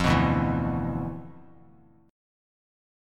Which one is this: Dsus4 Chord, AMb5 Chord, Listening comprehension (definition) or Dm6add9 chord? Dm6add9 chord